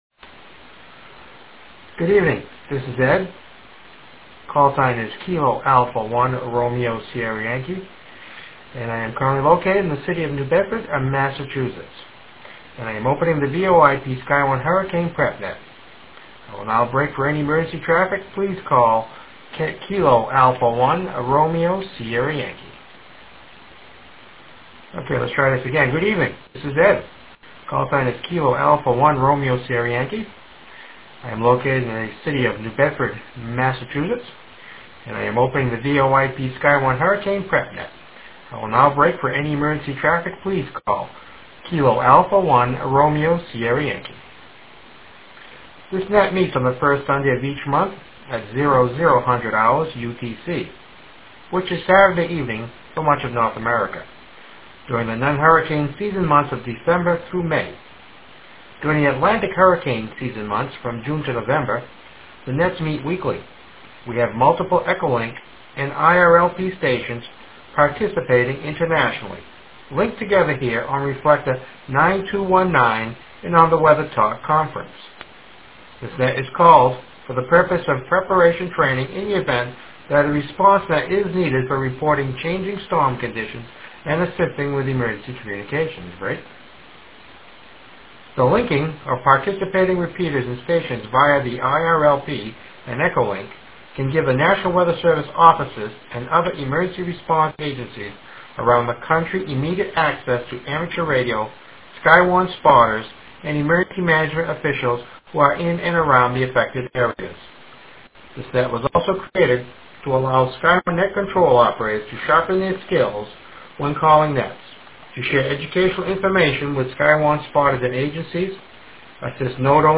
march_2010_national_hurricane_conference_and_noreaster_presentation.mp3